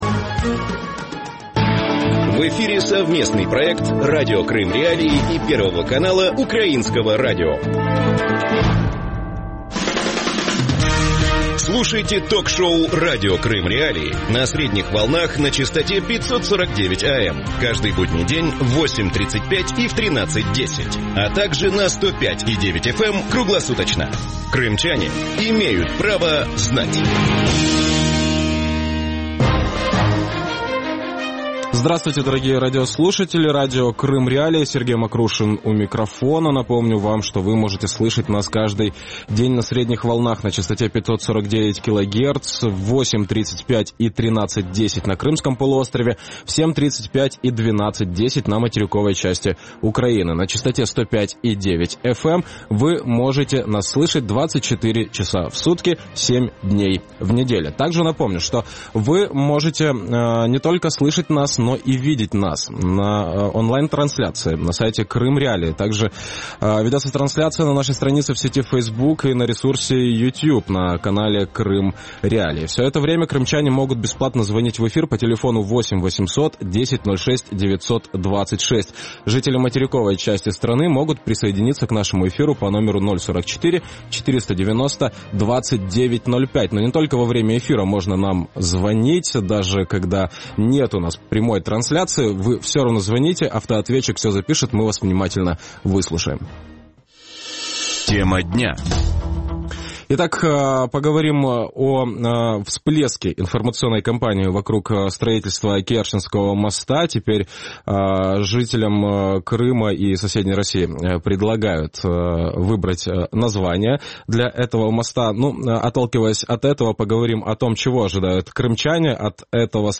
Радио Крым.Реалии эфире 24 часа в сутки, 7 дней в неделю.